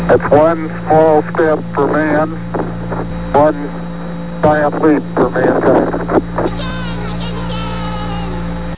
Other than the evidence I present here information is a bit sketchy though I have heard talk of the existance of 'TT' autopsy footage (very nasty), an unconfirmed NASA sound recording of Neil Armstrong giving his famous 'One Small Step' speech on the moon accompanied by what seems to be background shouts of 'a-gen,a-gen,a-gen', and vague rumours of strange shower-head like objects unearthed in Mexico.
neilarmstrong_thetruth.wav